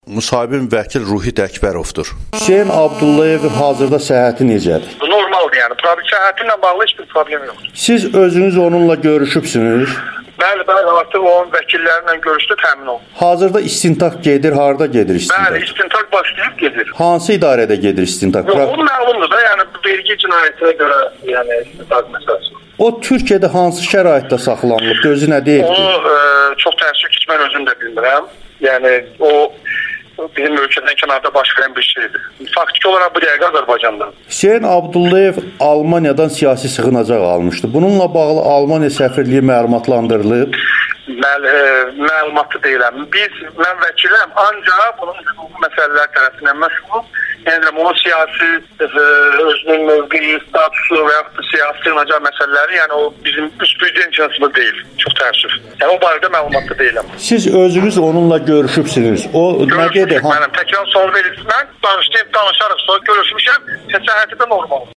Vəkil: Hüseyn Abdullayevlə bağlı istintaq davam etdirilir [Audio-Müsahibə]